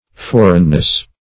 foreignness - definition of foreignness - synonyms, pronunciation, spelling from Free Dictionary
Foreignness \For"eign*ness\, n.